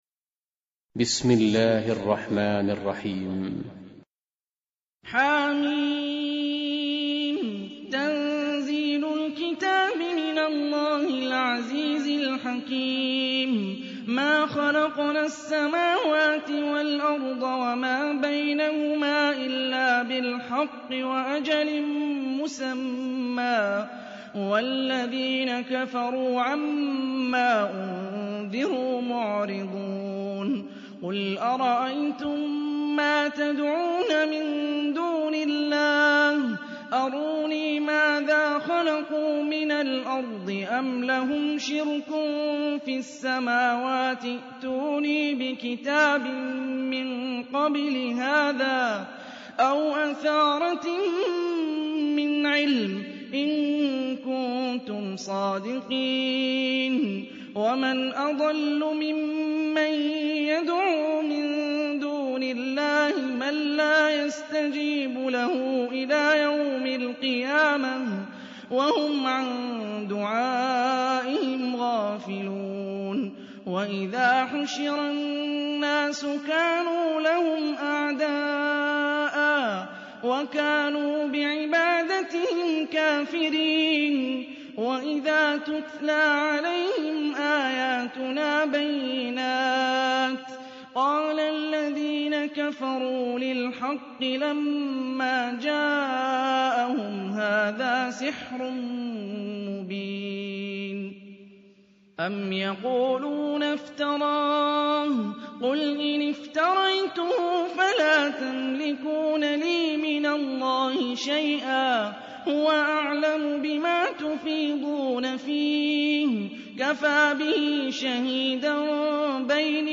Қуръони карим тиловати, Қорилар. Суралар Qur’oni karim tilovati, Qorilar.